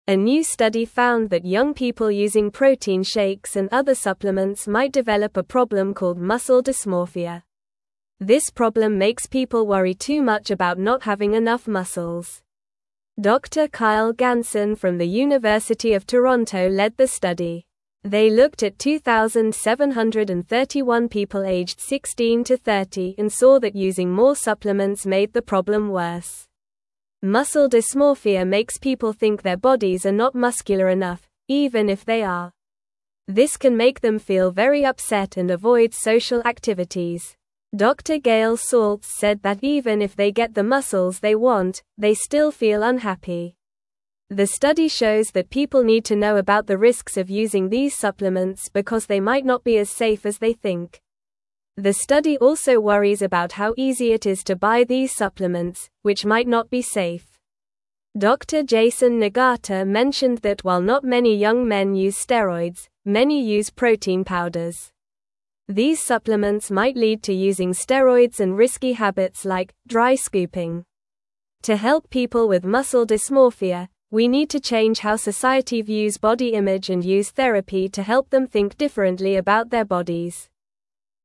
Normal
English-Newsroom-Lower-Intermediate-NORMAL-Reading-Worrying-About-Muscles-Can-Make-You-Unhappy.mp3